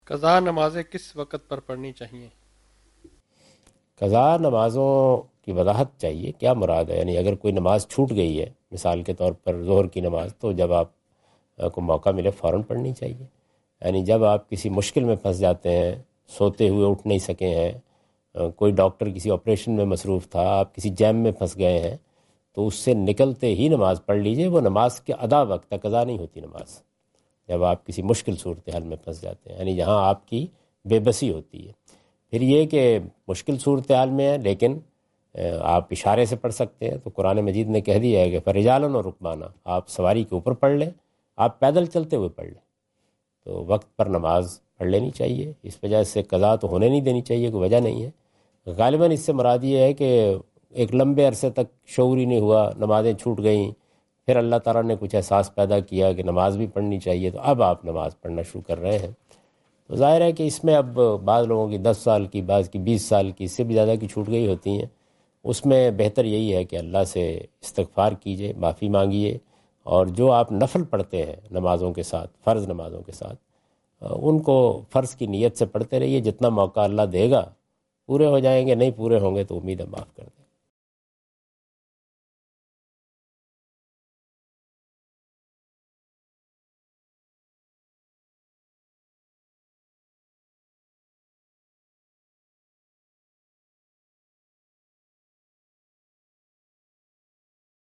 Javed Ahmad Ghamidi answer the question about "Compensating for Missed Prayers" during his Australia visit on 11th October 2015.
جاوید احمد غامدی اپنے دورہ آسٹریلیا کے دوران ایڈیلیڈ میں "قضا نماز کب ادا کریں؟" سے متعلق ایک سوال کا جواب دے رہے ہیں۔